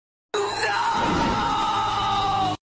Sound Buttons: Sound Buttons View : Spiderman Screaming No
spiderman-screaming-no.mp3